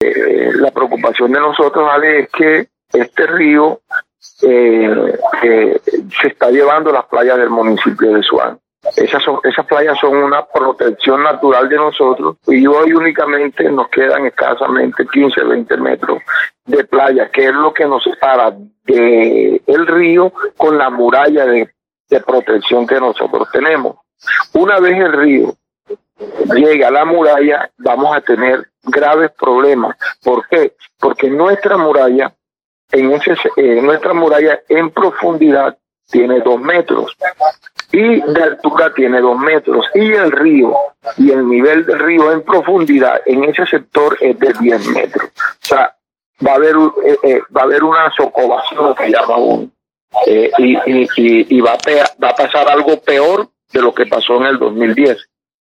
El nivel del río ya casi llega a los 6 metros y registra un crecimiento diario de 4 centímetros, explicó el alcalde, Danilo Cabarcas.
VOZ-ALCALDE-SUAN-CABARCAS.mp3